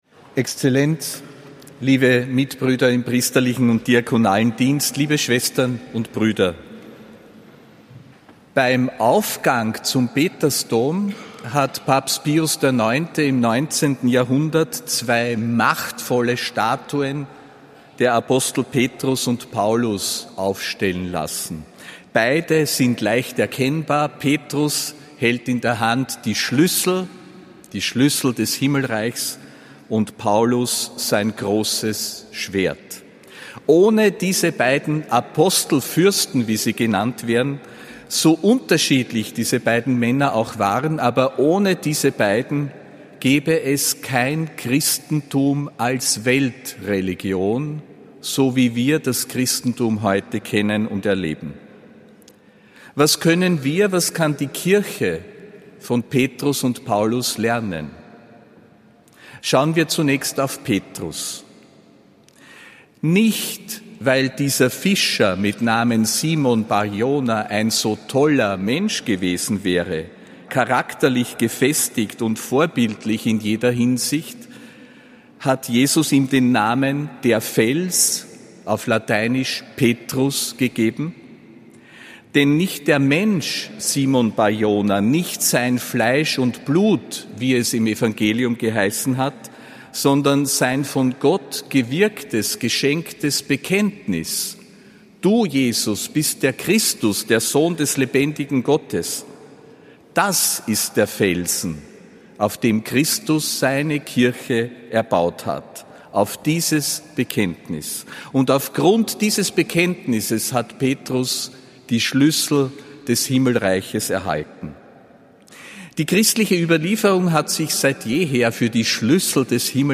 Predigt des Apostolischen Administrators Josef Grünwidl zum Hochfest Petrus und Paulus am 29. Juni 2025.